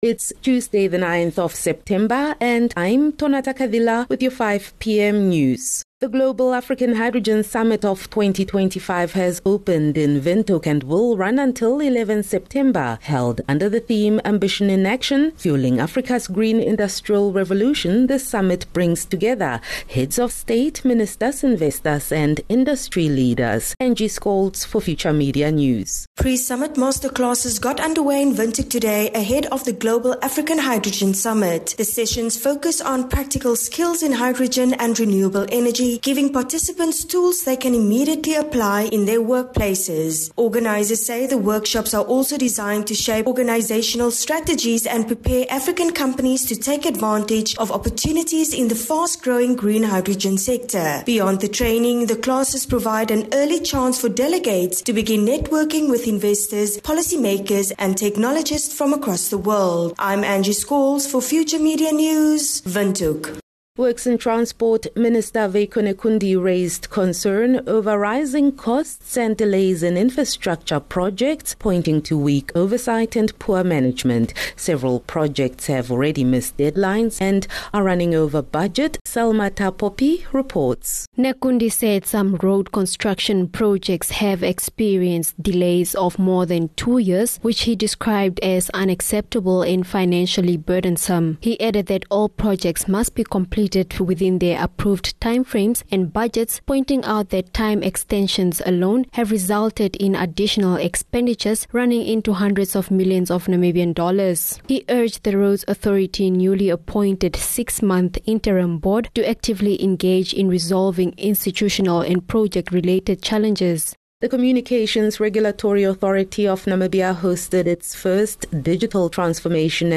9 Sep 9 September - 5 pm news